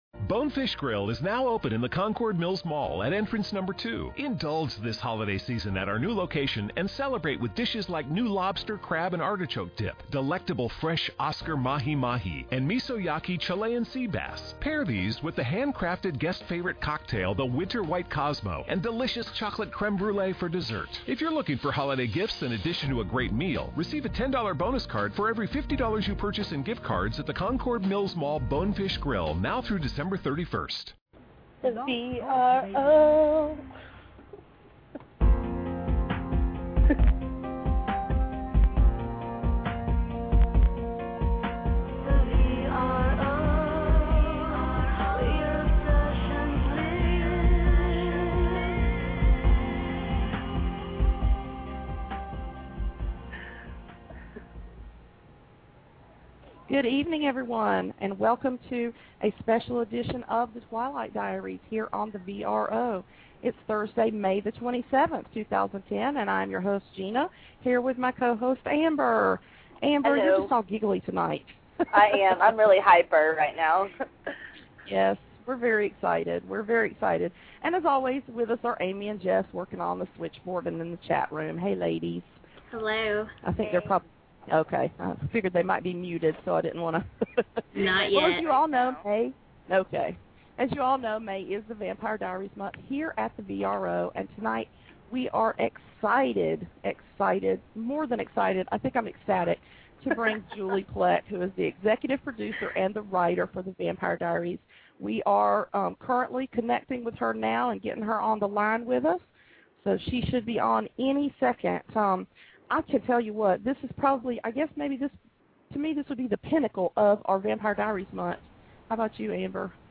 Julie Plec executive producer of "The Vampire Diaries" will join us to talk about the 1st season of "The Vampire Diaries".